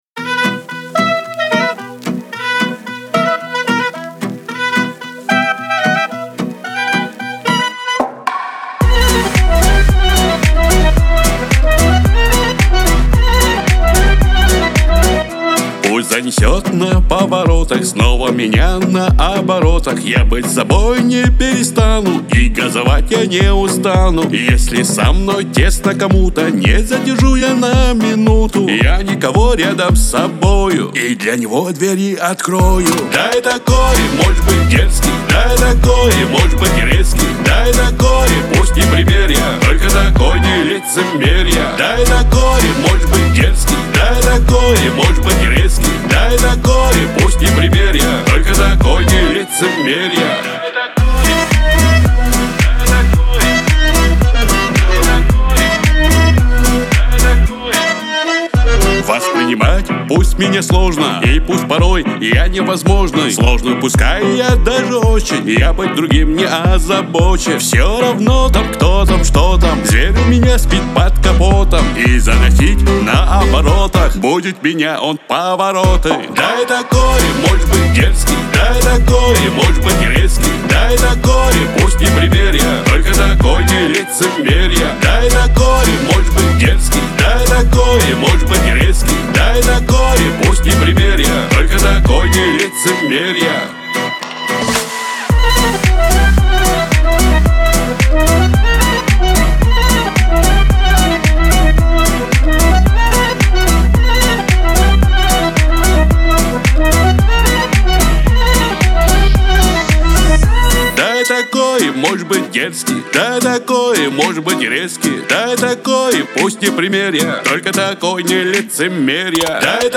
Кавказ – поп
Лирика